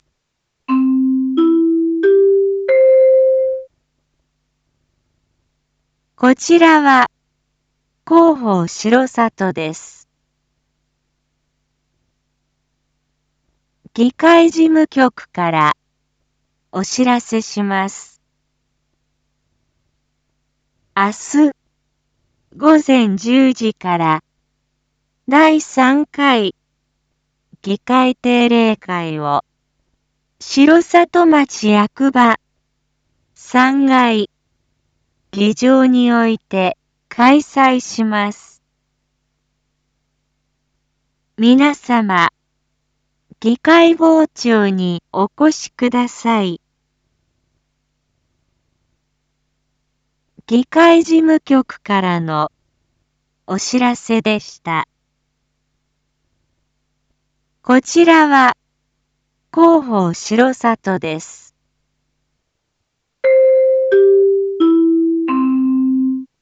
一般放送情報
Back Home 一般放送情報 音声放送 再生 一般放送情報 登録日時：2023-09-04 19:01:07 タイトル：9/4 19時 第3回議会定例会 インフォメーション：こちらは広報しろさとです。